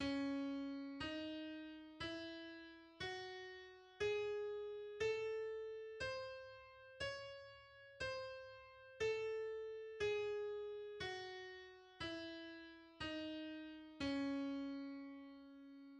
The C-sharp harmonic minor and melodic minor scales are: